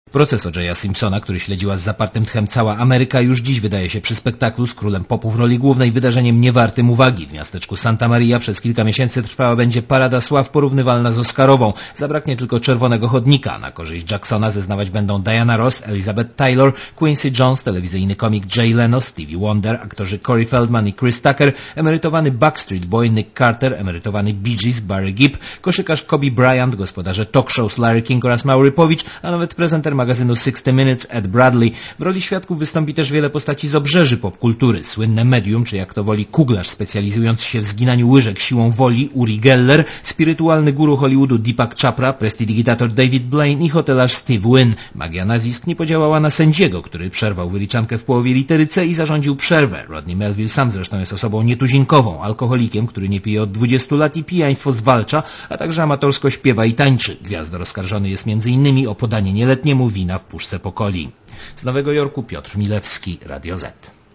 Korespondecja z USA